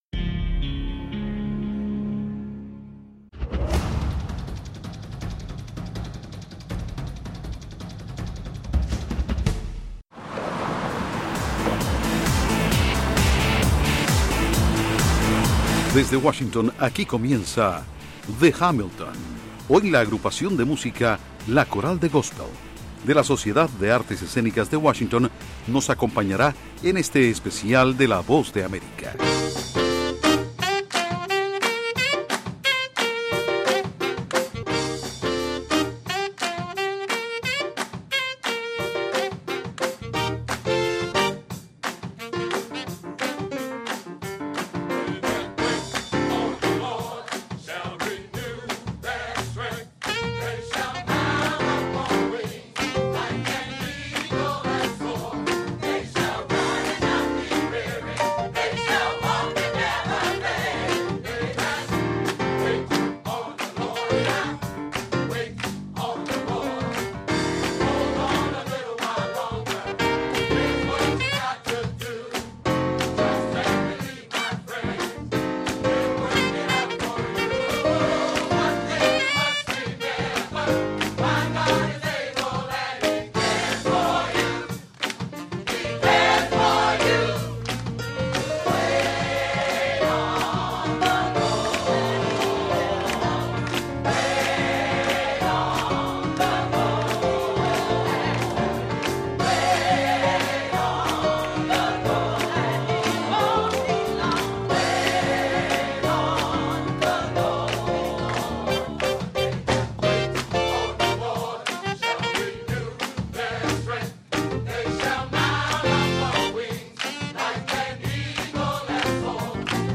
Música góspel con un increíble coro
Desde el hotel de la capital de Estados Unidos, The Hamilton, llega un especial musical muy espiritual con la invitación especial del coro de música góspel WPAS.